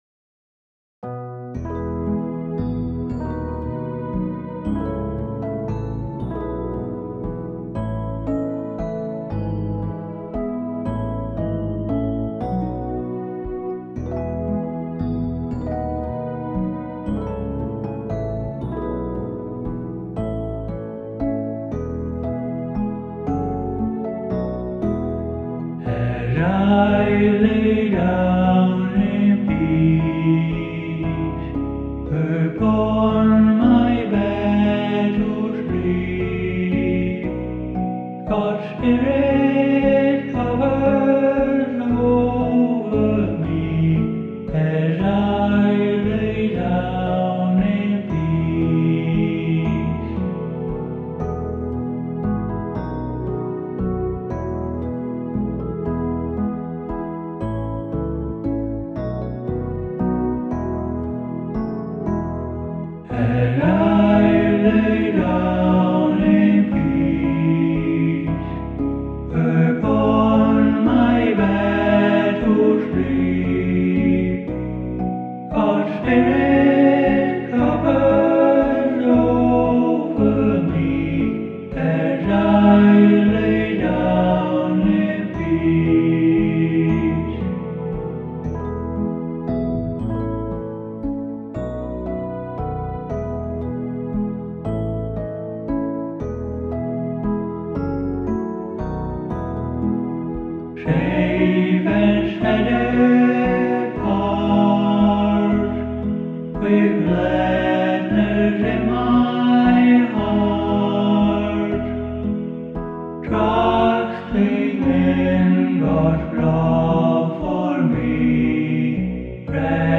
Other Lullabies